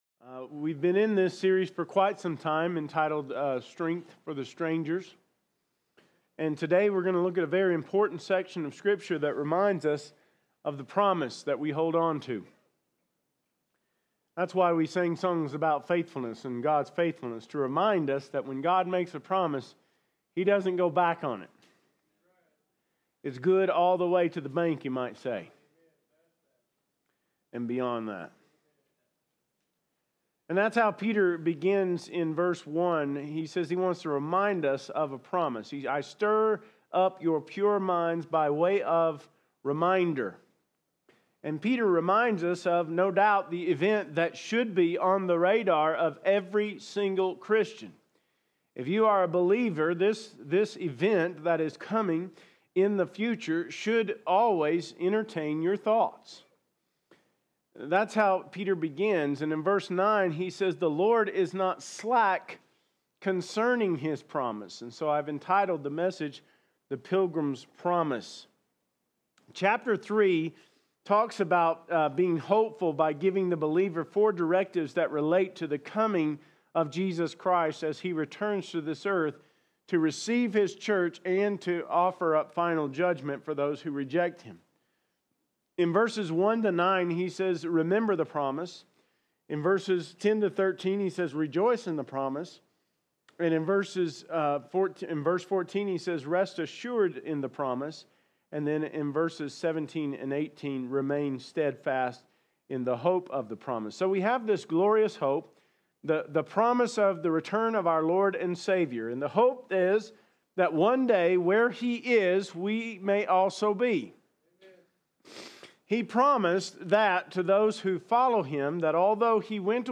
2025 Sermons - Smith Valley Baptist Church